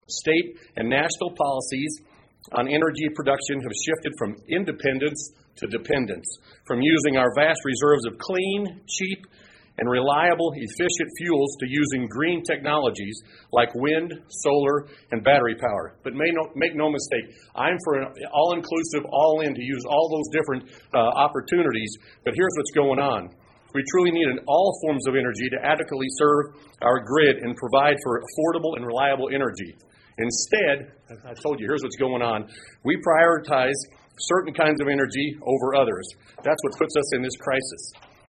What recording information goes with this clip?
Several downstate Republican legislators joined together at the Capitol Tuesday calling for action to address the rising cost of energy impacting Illinois families and businesses.